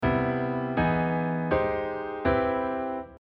A major third chord cycle using major seventh chords